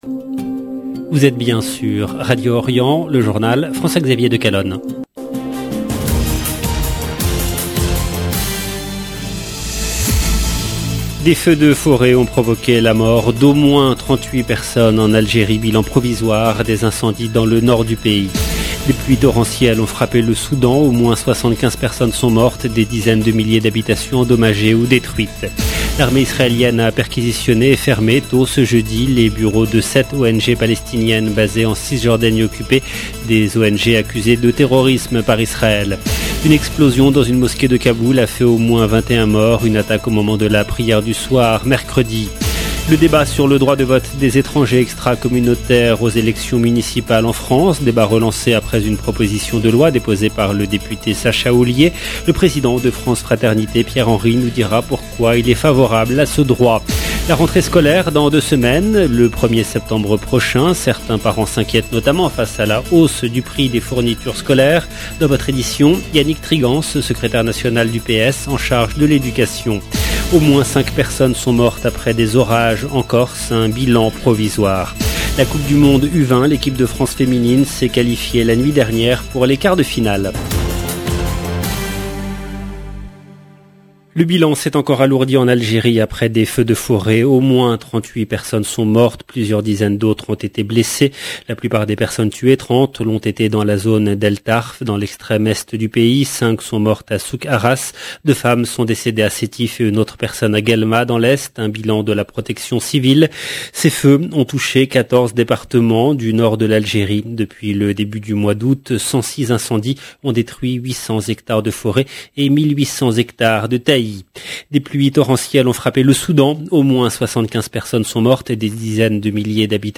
LE JOURNAL DU SOIR EN LANGUE FRANCAISE DU 18/8/2022